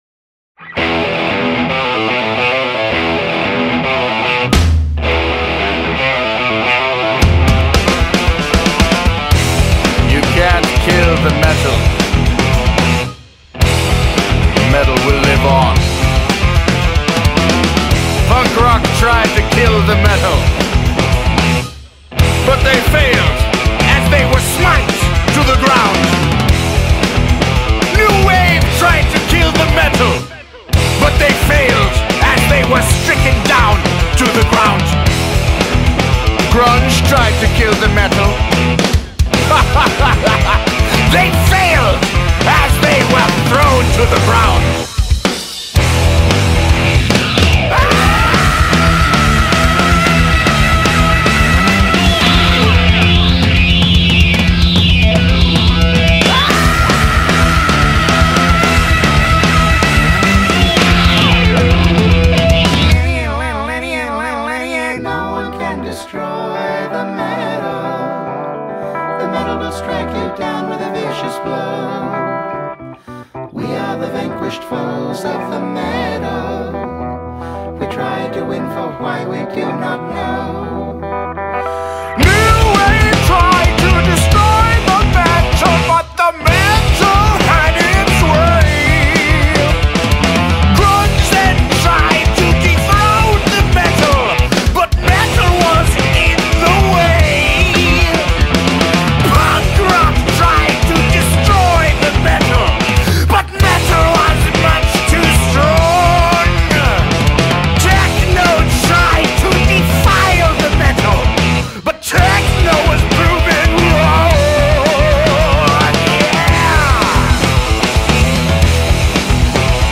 BPM112
Audio QualityPerfect (Low Quality)